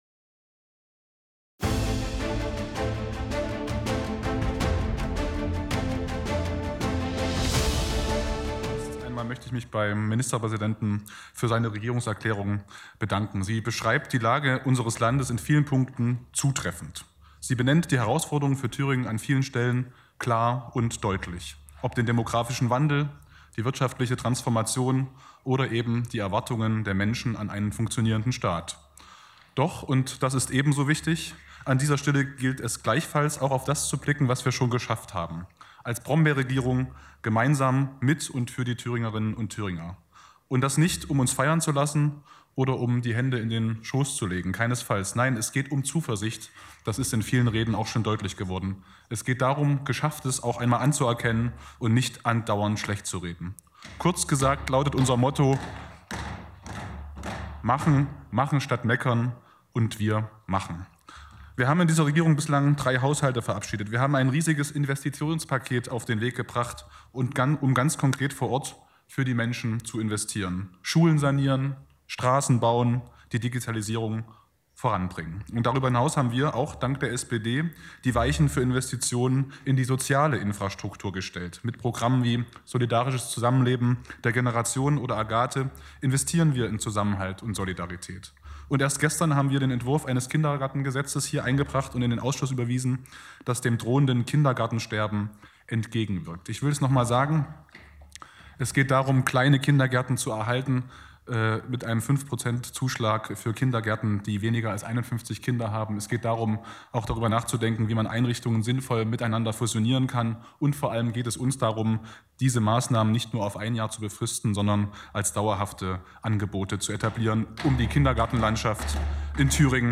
In unserem Gespr�ch geht es um die generalistische Pflegefachausbildung, die Last der B�rokratie und einiges mehr. So kann man erkennen, was sich bereits getan hat und welche Schritte die Politik noch einleiten m�sste.